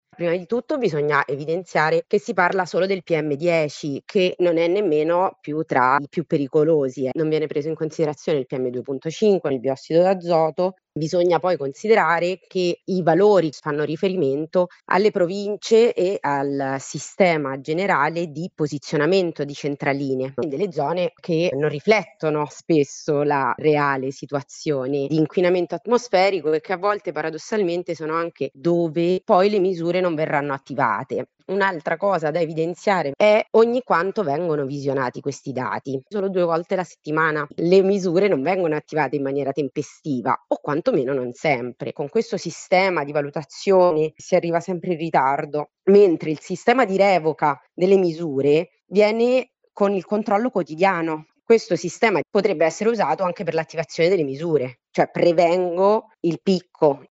Attivate misure antismog in Lombardia – intervista